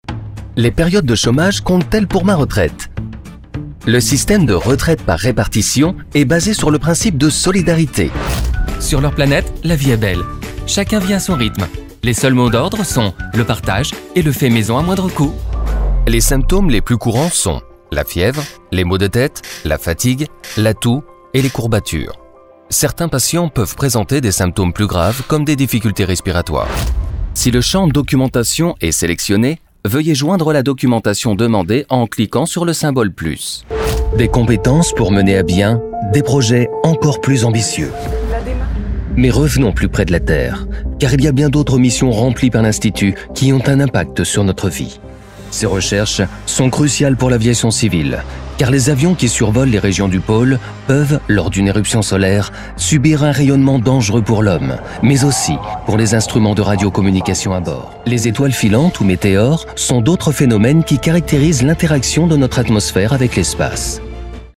Démos Voix-Off
Les démos sur cette page sont des extraits de projets réels livrés et mixés par les studios respectifs.